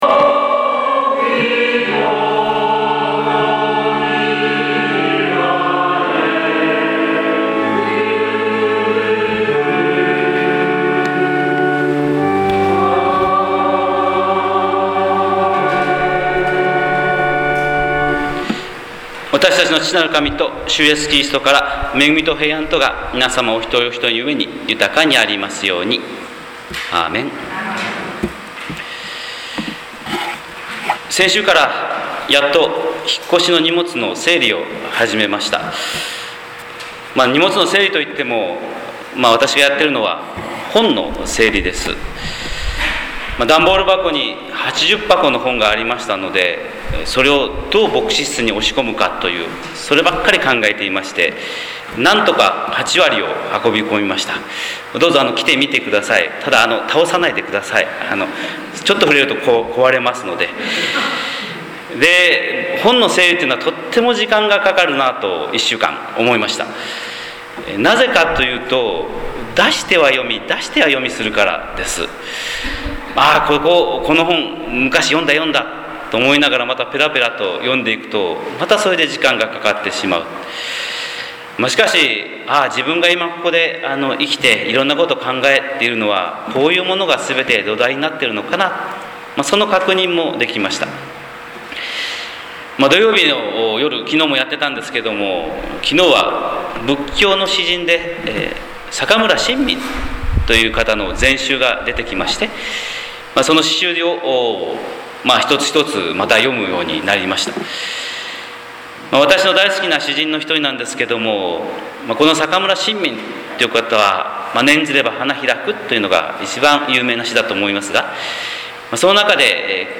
神様の色鉛筆（音声説教）
日本福音ルーテル教会（キリスト教ルター派）牧師の朝礼拝説教です！